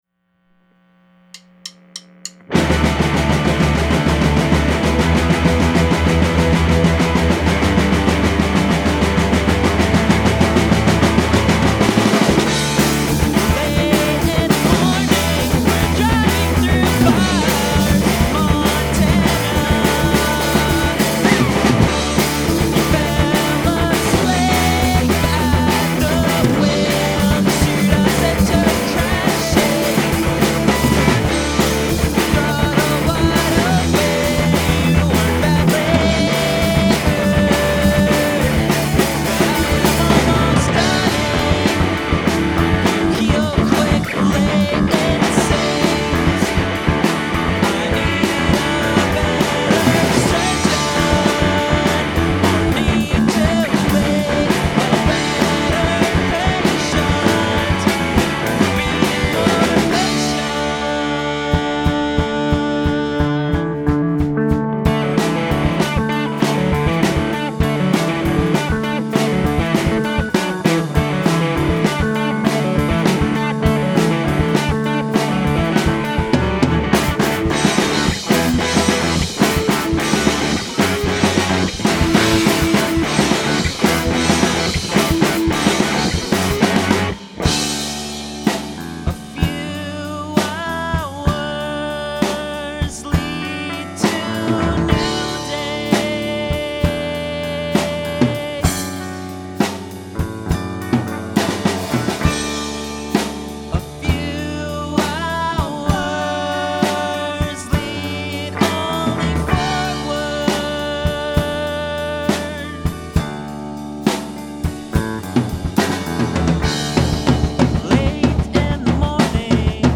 recorded at bartertown studios